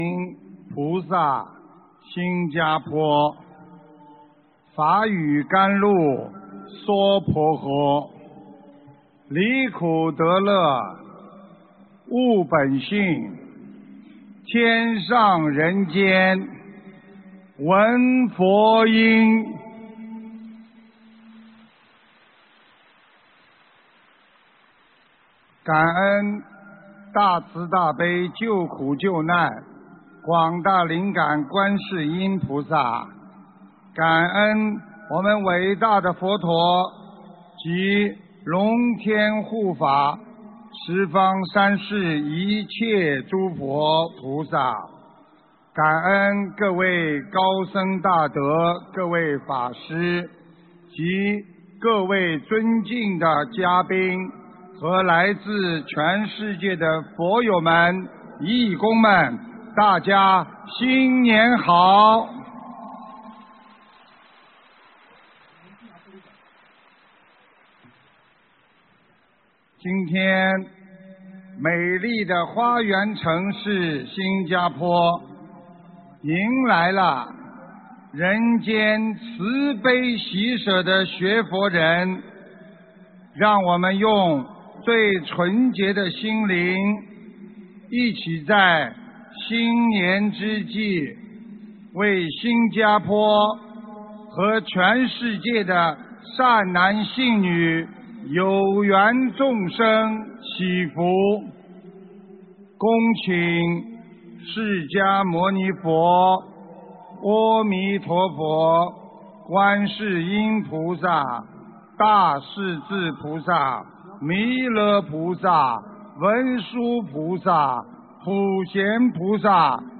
【2017新加坡】2月18日 8万人灵山大法会 文字+音频 - 2017法会合集 (全) 慈悲妙音